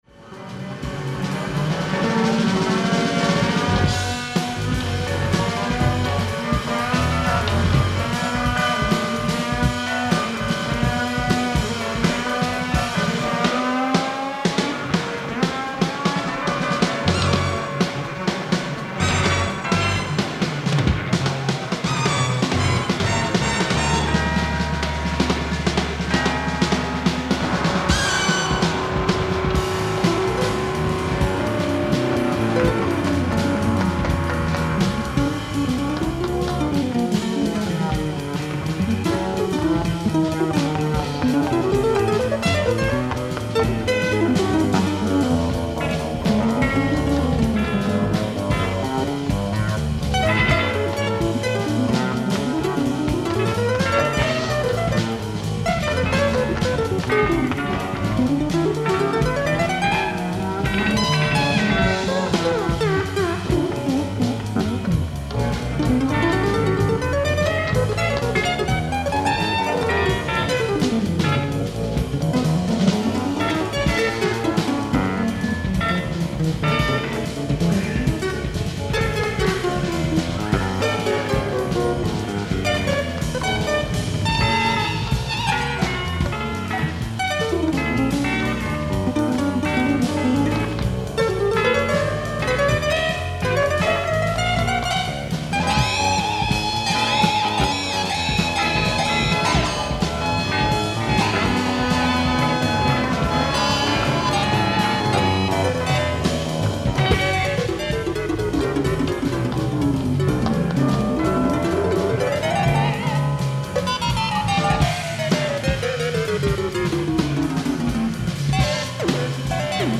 ライブ・アット・オープンシアター・イースト、東京 07/28/1984
※試聴用に実際より音質を落としています。